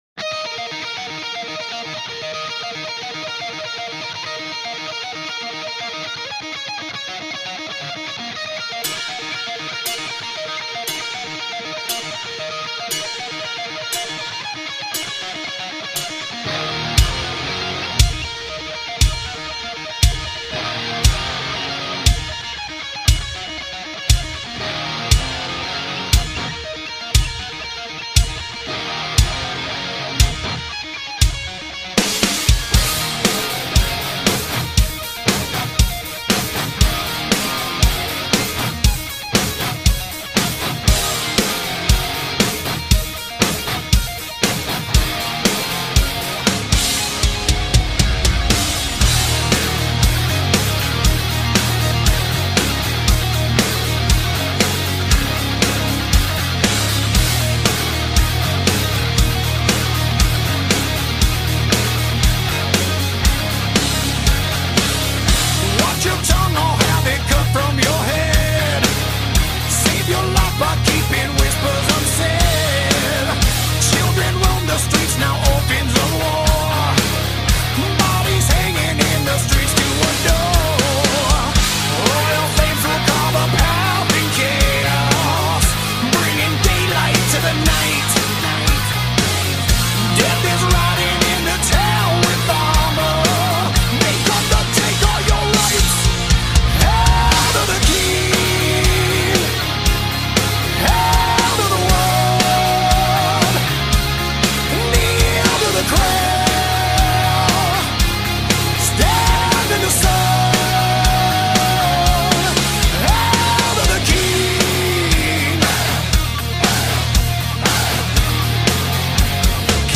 Metal Rock Hard Rock heavy metal
متال راک هارد راک هوی متال